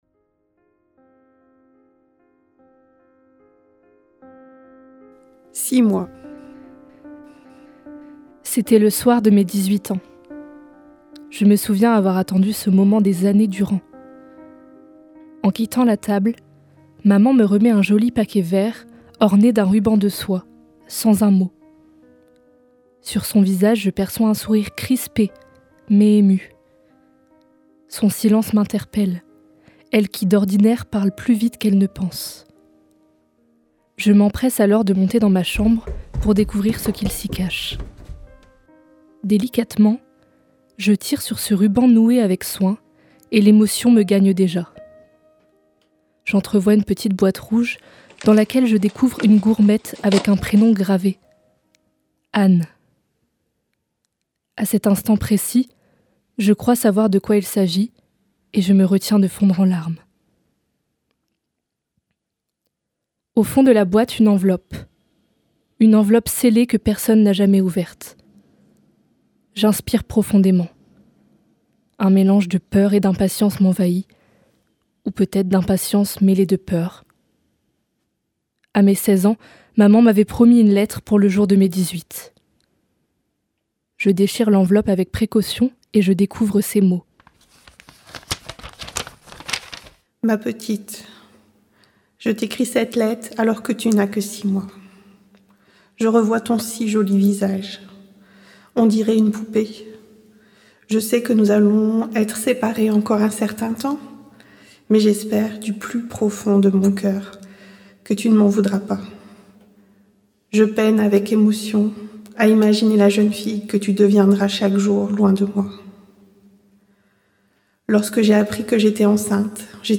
🎧 Six mois - Radiobook
Fiction enregistrée et montée dans le cadre des ateliers menés avec le SUAC.